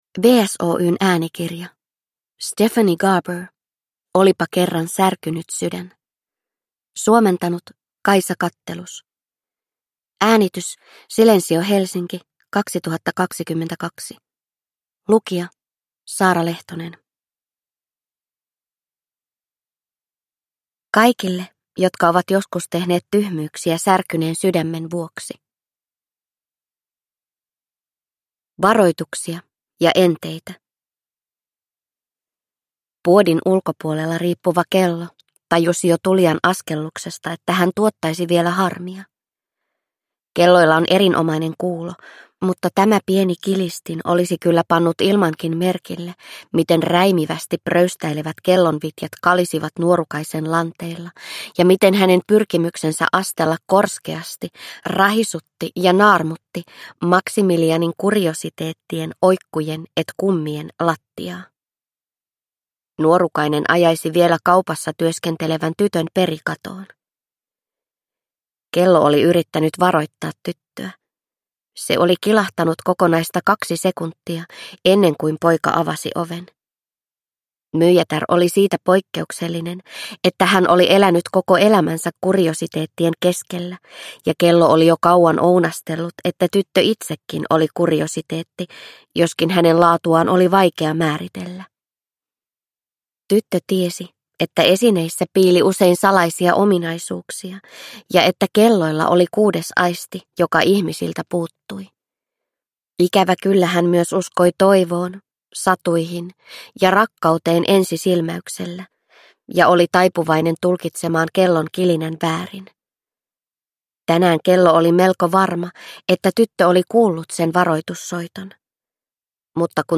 Olipa kerran särkynyt sydän – Ljudbok – Laddas ner